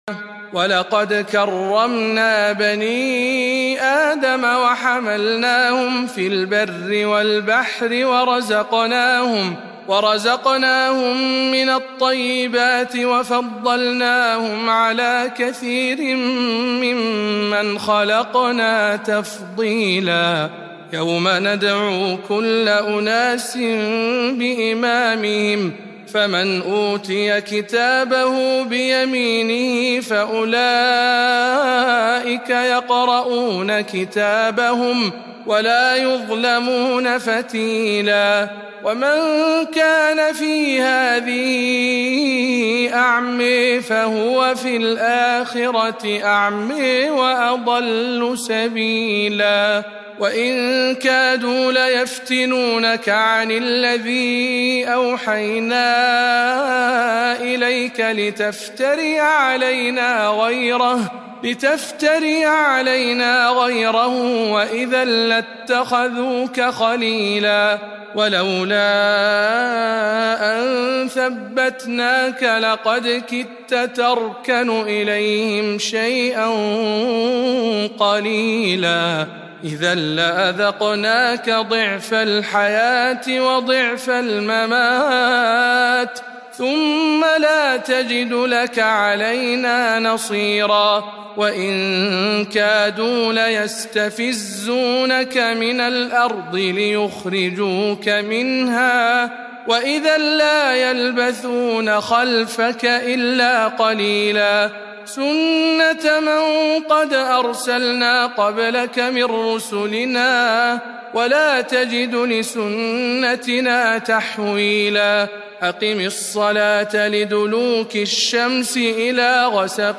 تلاوة مميزة من سورة الإسراء